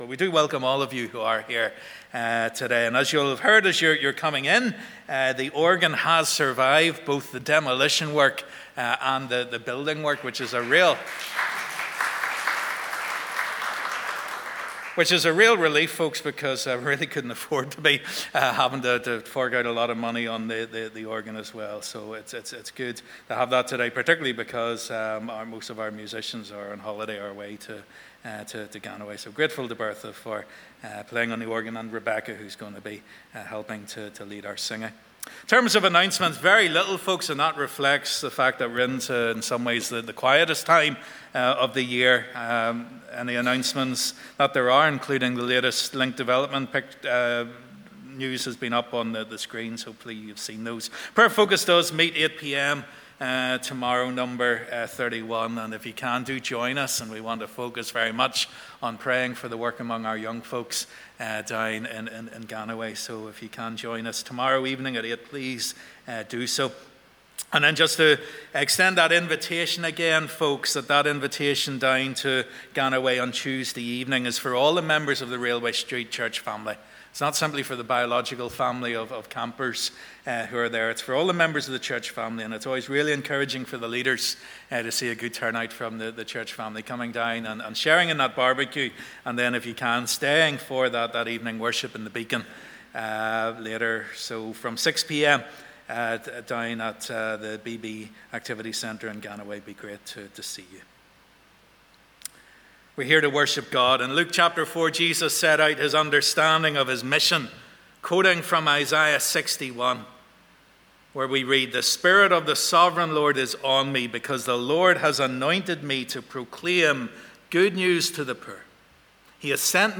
Live @ 10:30am Morning Service